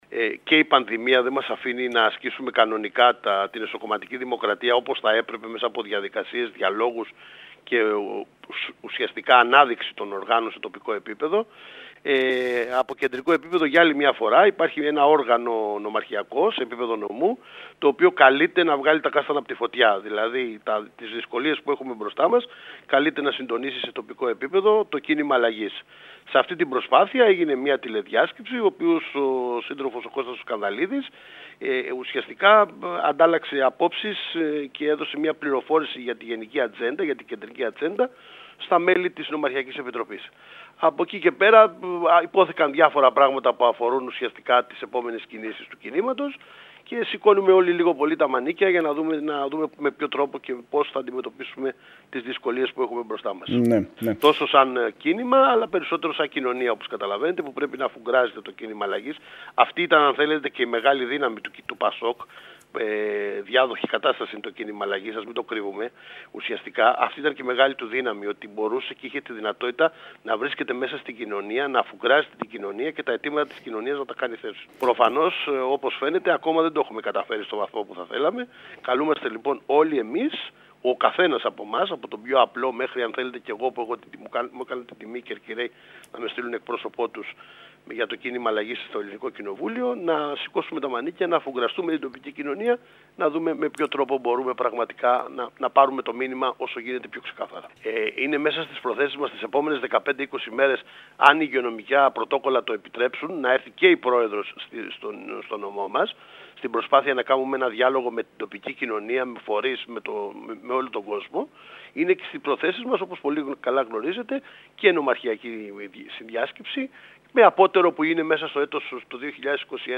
Στις διαδικασίες εσωκομματικής ανασύστασης του χώρου αναφέρθηκε, μιλώντας στην ΕΡΤ Κέρκυρας, ο βουλευτής του ΚΙΝΑΛ, Δημήτρης Μπιάγκης.